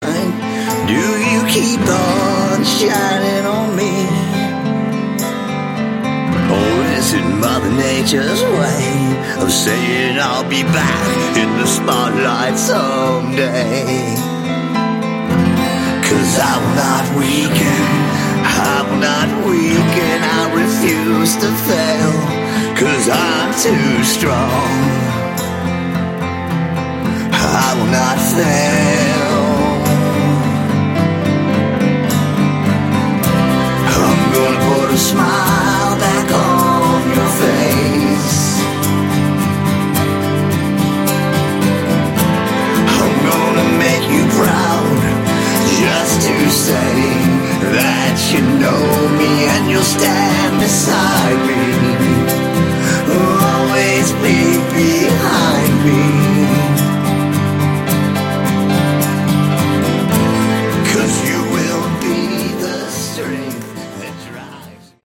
Category: Sleaze Glam/Punk
lead vocals, guitars, keyboards, piano
guitars, backing vocals